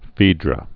(fēdrə, fĕdrə)